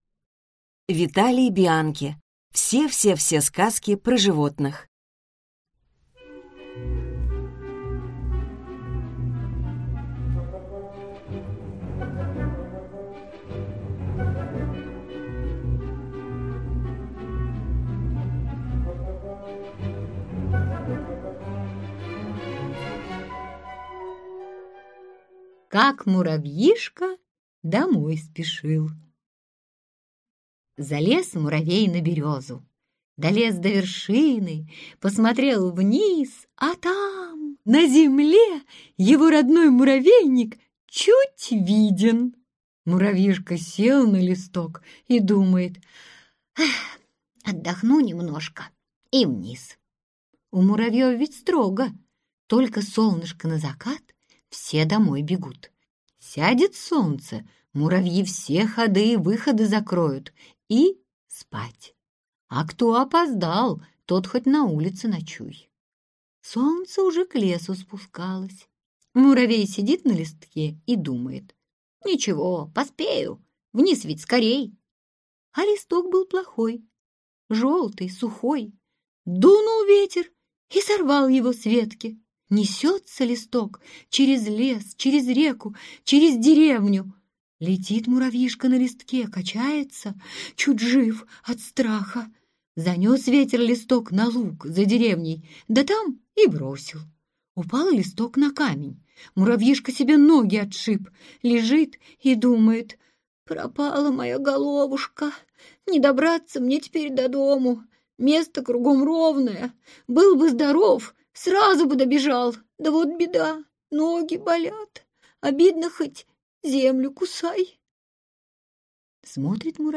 Аудиокнига Все-все-все сказки про животных | Библиотека аудиокниг